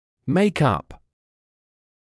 [meɪk ʌp][мэйк ап]составлять что-то (о компонентах, составляющих одно целое)